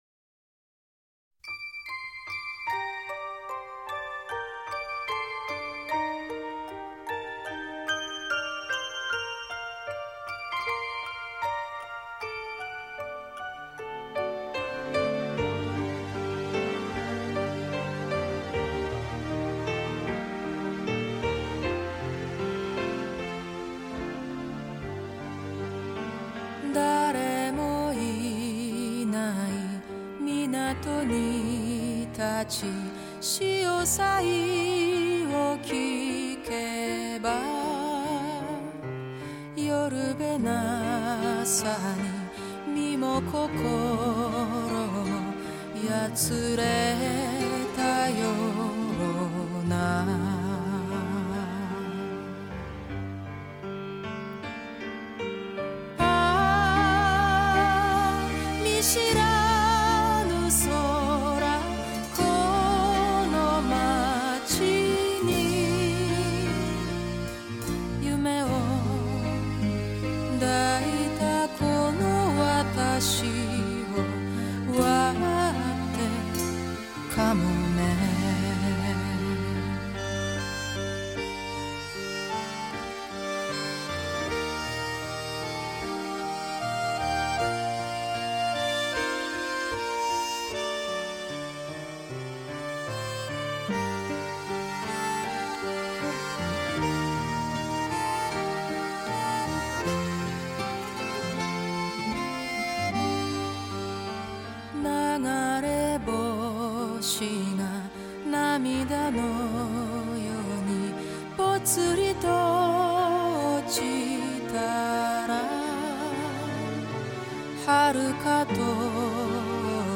淡定从容 清雅深邃的歌声